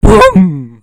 line_clear.ogg